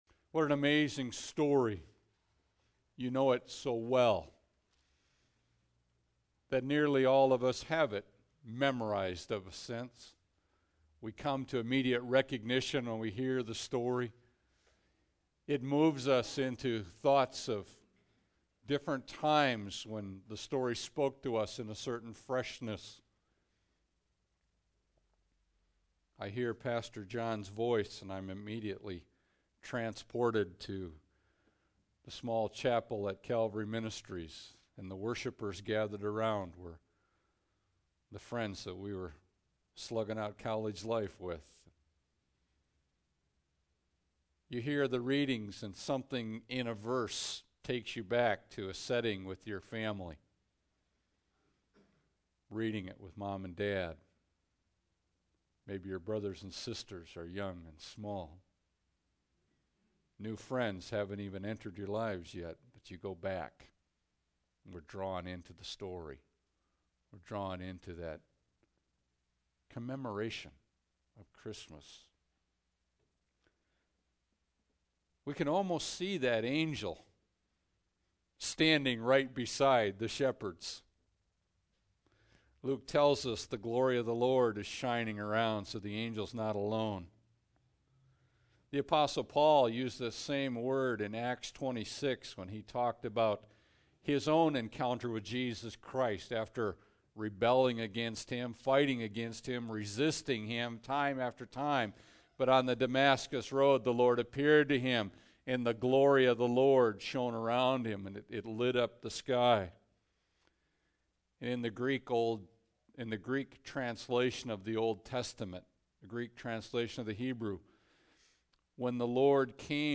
Luke 2 Service Type: Special Service Bible Text